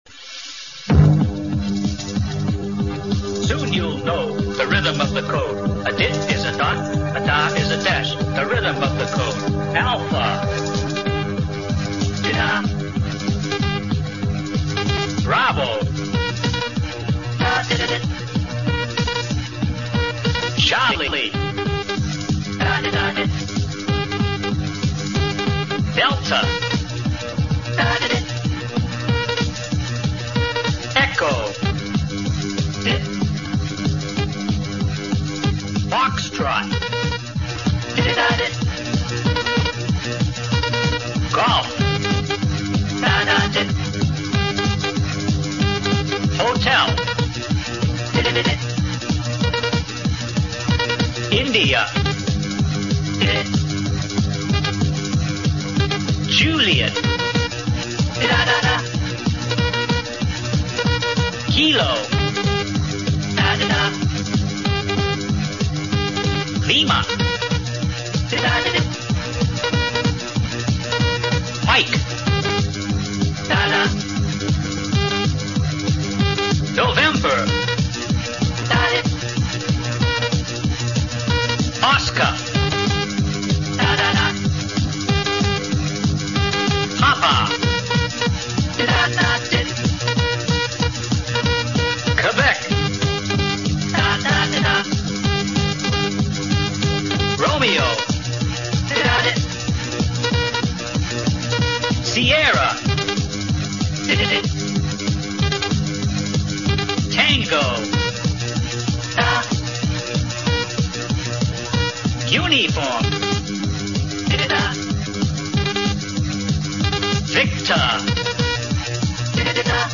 Scambler - Descrambler APRS maps Isère - France The FT847 used as cross-band repeater FT50 Yaesu setup by software FT51 Yaesu setup by software rythm'n CW : learn CW with music Audio output recorder with squelch
rythmn-CW.58.wav